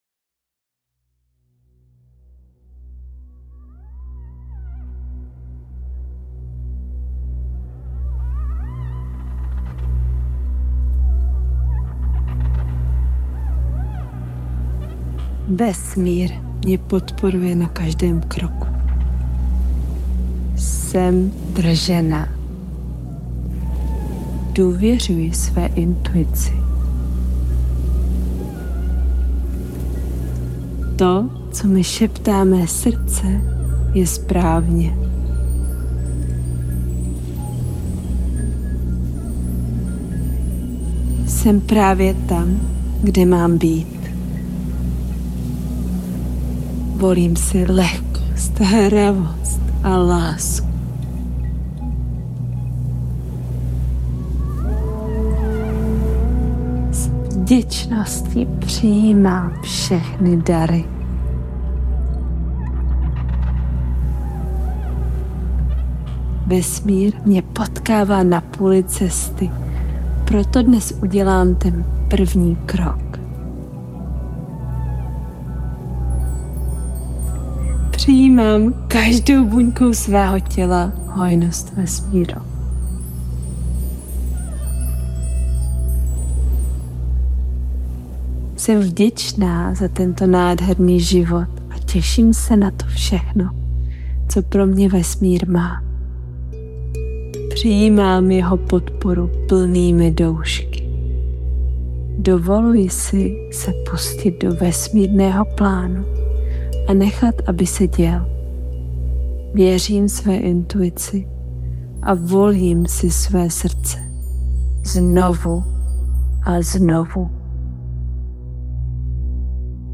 Vibruj Výš Afirmační Meditace
Extra tip: Tuto meditaci můžeš také použít jako podkresovou hudbu, která podporuje vibraci tvého prostoru a nechat ji hrát dokola.